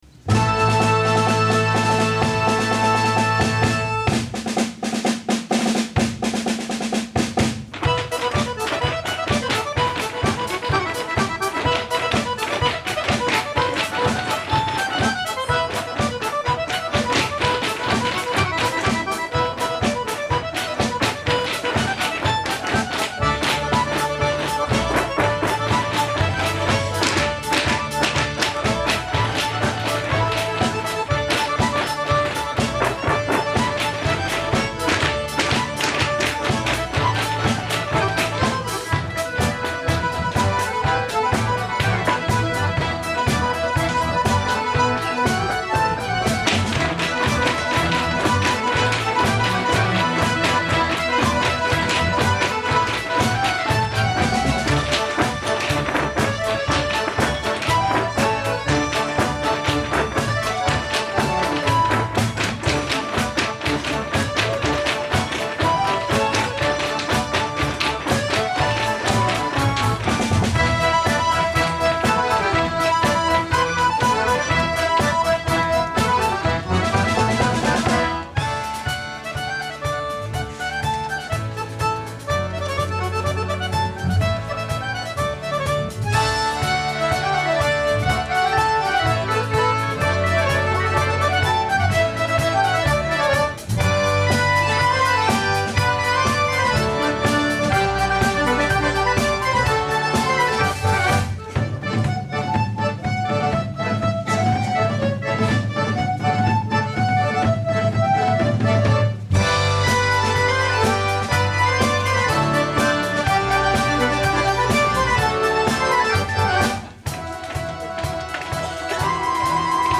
1.Akkordeon-Orchester Rheinhausen 1950 e.V.
14.November 2004: Vereinskonzert
mp3 (Zugabe)